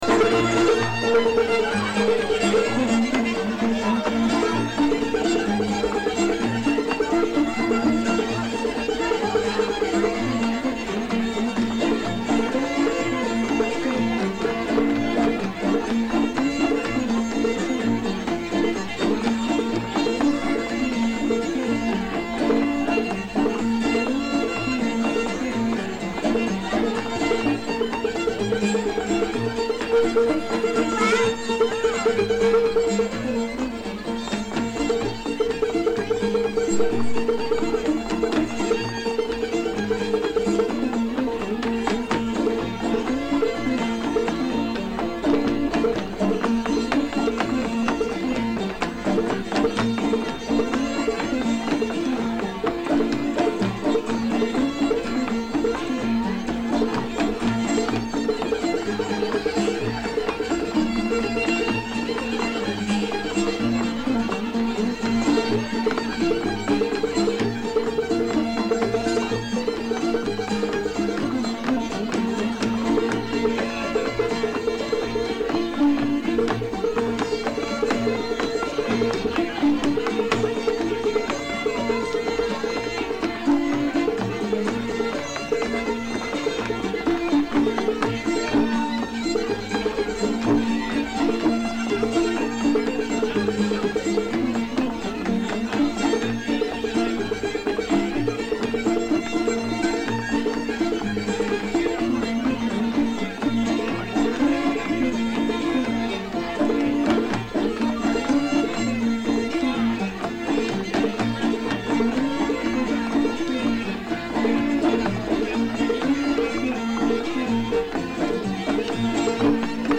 Mystery Tune (sounds a little like Sugarfoot Rag